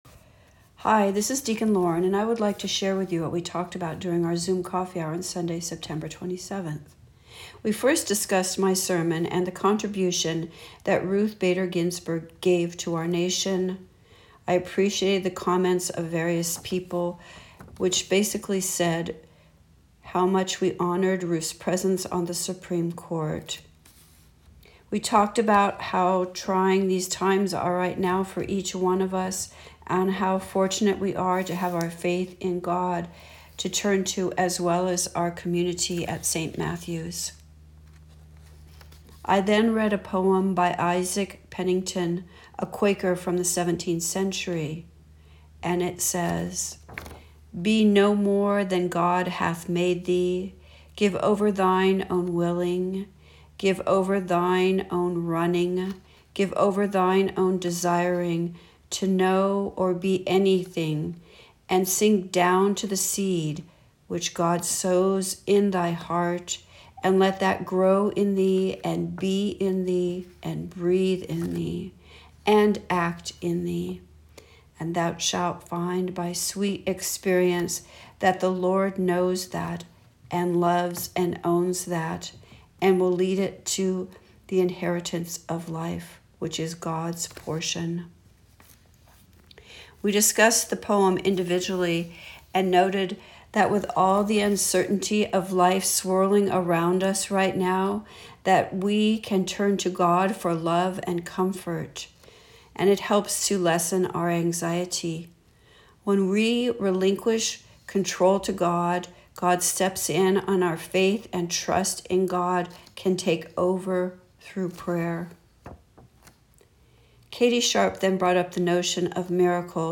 I would like to share with you what we talked about during our Zoom Coffee Hour on Sunday, September 27, 2020.